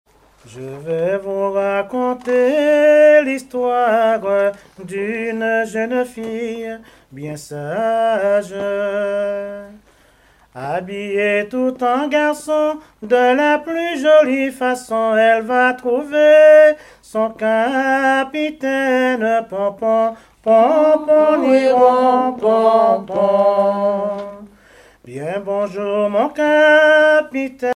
Genre strophique
chansons anciennes recueillies en Guadeloupe
Pièce musicale inédite